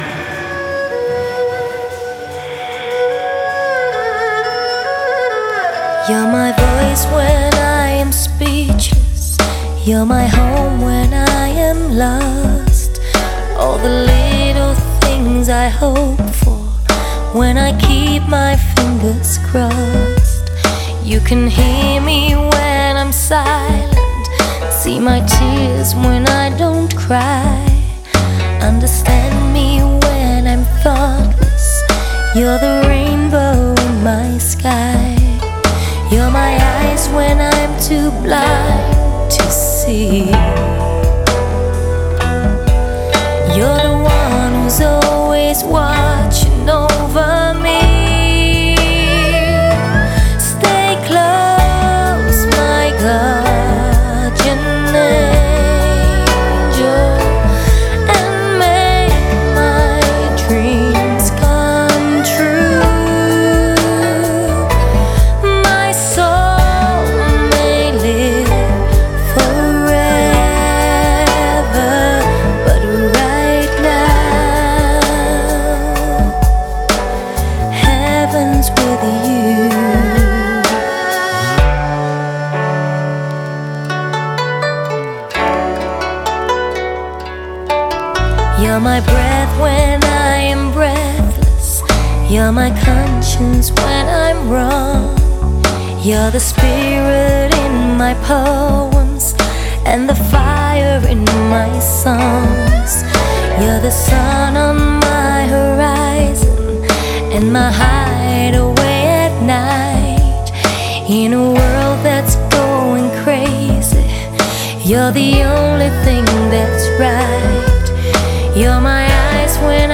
她是荷兰华裔歌手